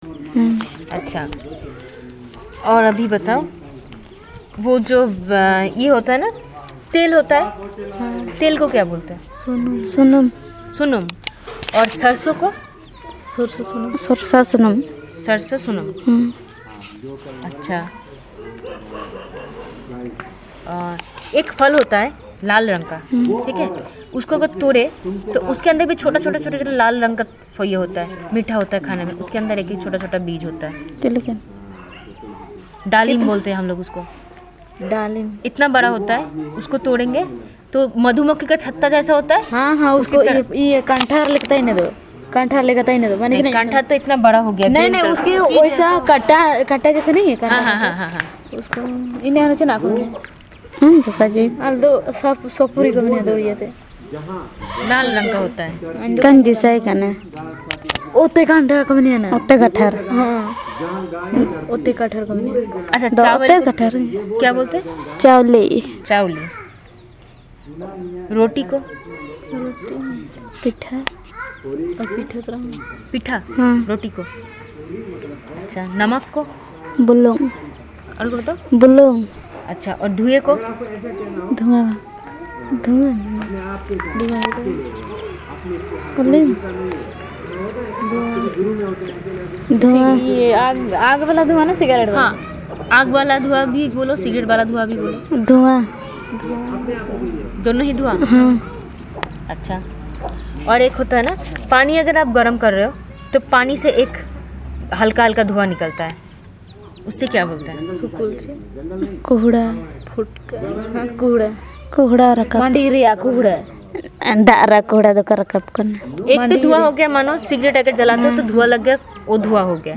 Elicitation of multi domain words used by the Birhor people